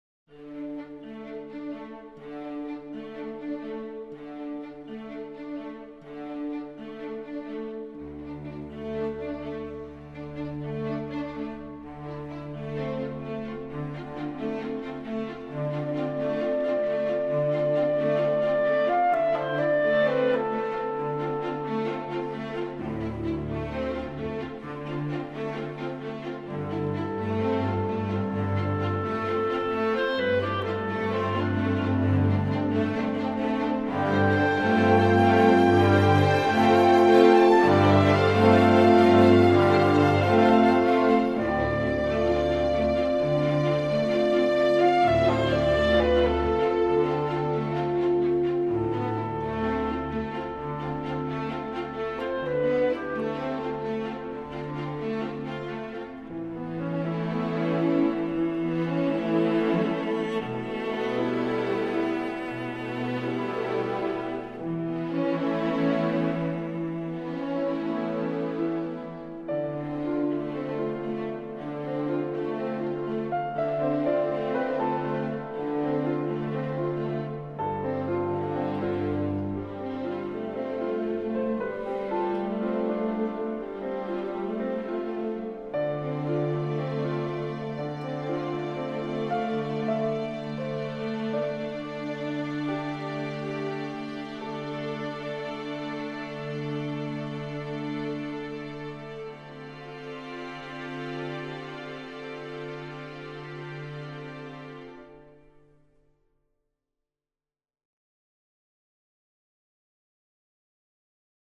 avec cordes
un piano